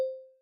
pause-continue-click.wav